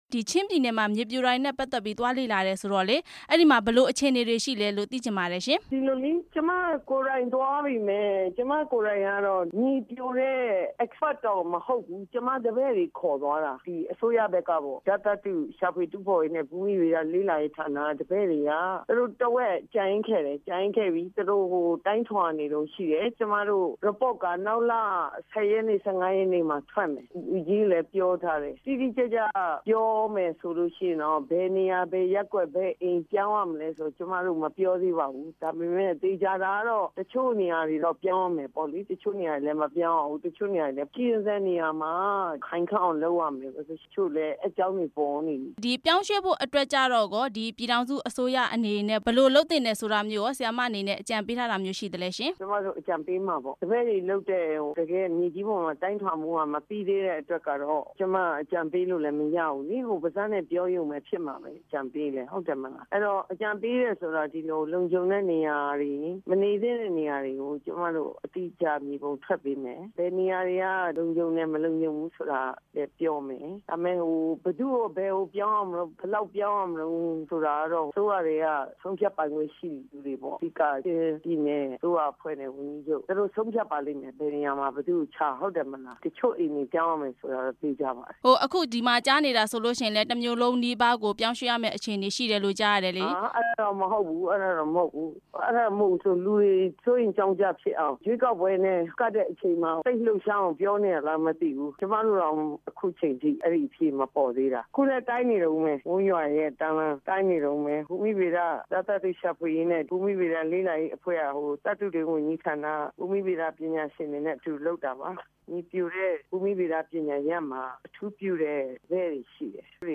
ဟားခါးမြို့ ပြောင်းရွှေ့ရေး သမ္မတအကြံပေးနဲ့ မေးမြန်းချက်